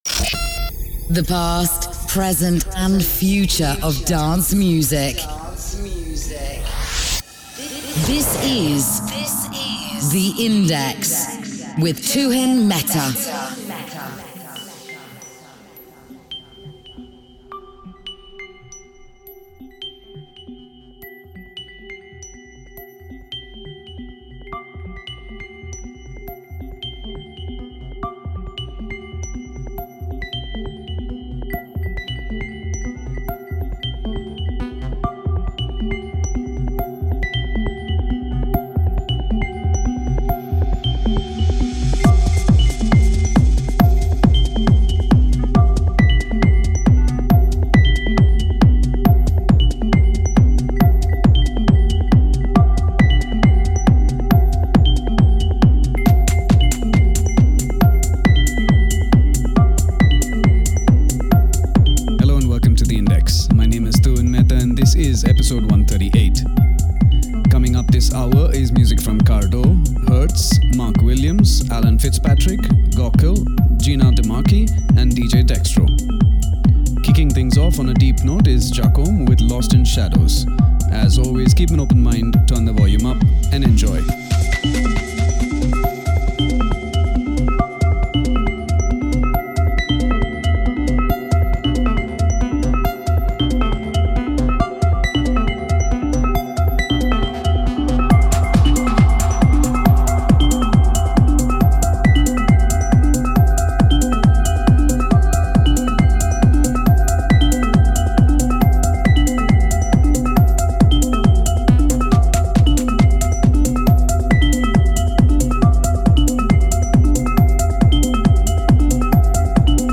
Techno
Dance